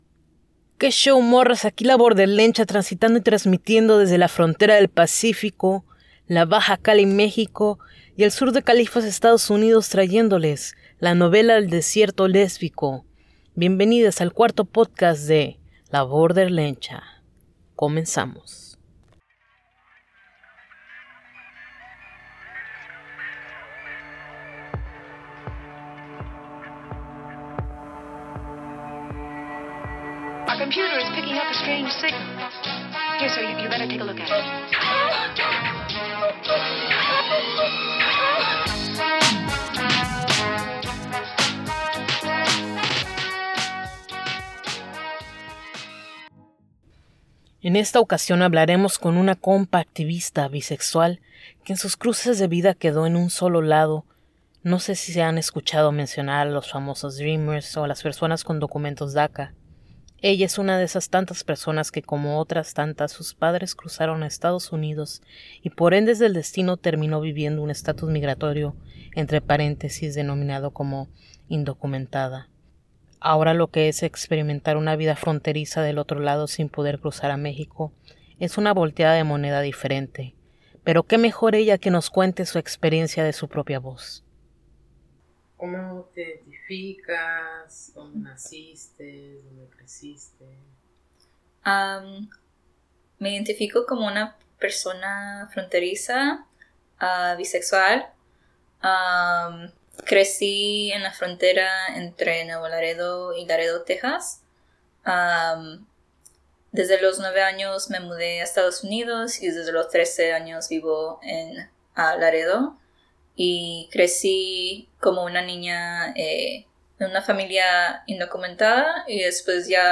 Entrevista a una persona con estatus migratorio de DACA